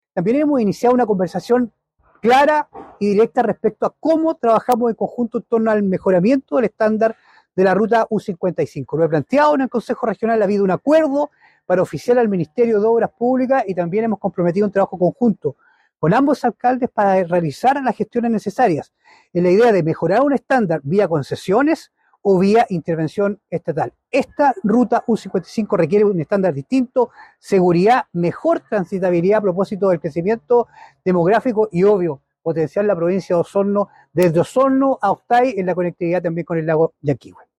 En la última sesión plenaria del Consejo Regional de Los Lagos, realizada en la comuna de Puerto Octay, se discutió la posibilidad de mejorar la ruta U-55, que conecta las comunas de Puerto Octay y Osorno.
El consejero regional por la provincia de Osorno, Francisco Reyes, enfatizó la importancia de esta medida para realizar las gestiones necesarias en la idea de mejorar un estándar vía concesiones o vía intervención estatal.